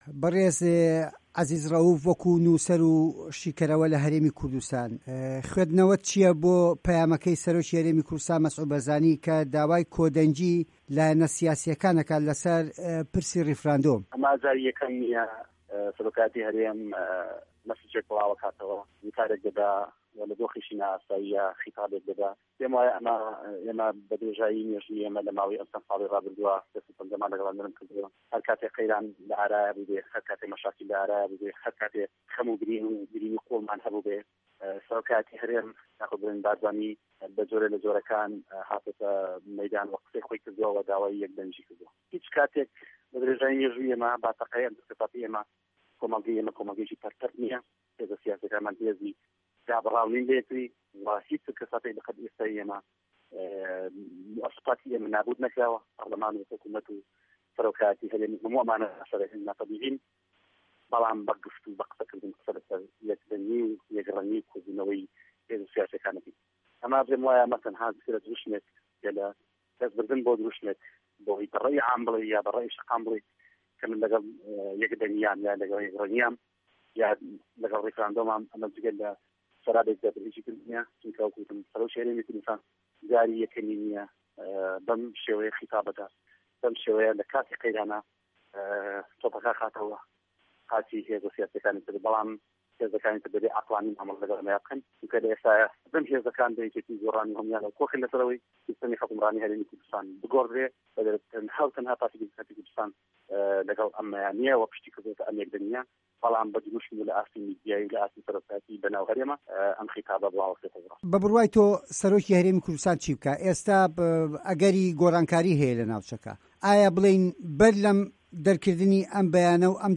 ده‌قی وتوێژه‌که‌ له‌م فایله‌ ده‌نگییه‌دایە